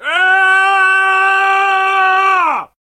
scream6.ogg